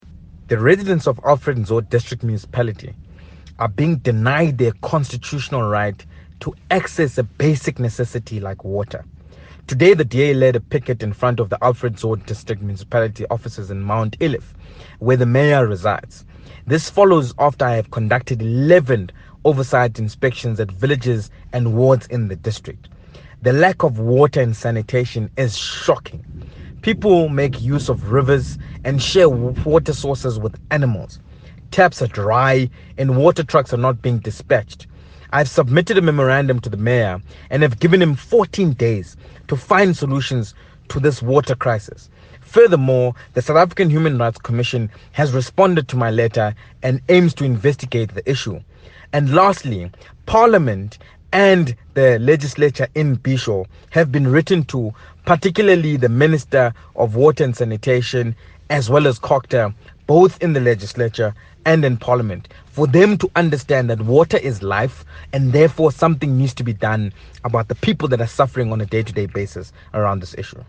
Note to Editors: Please find attached soundbites in